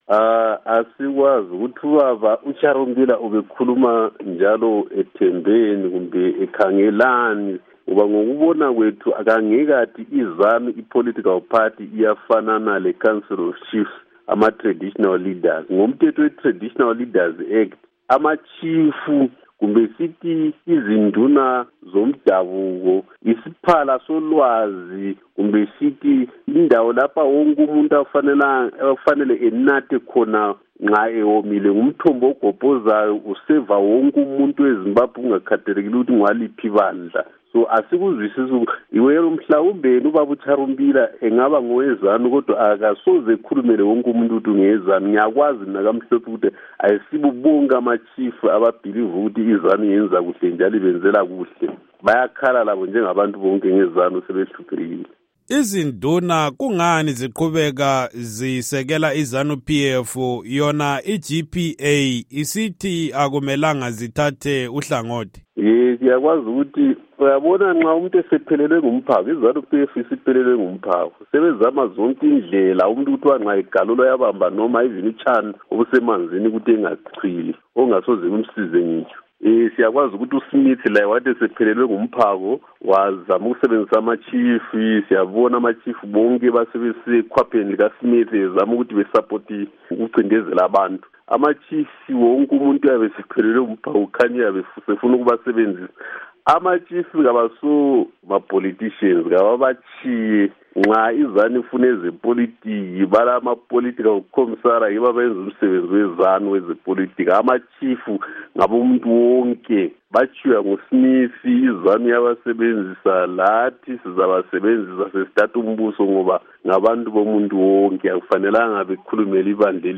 Ingxoxo LoMnu Joel Gabuza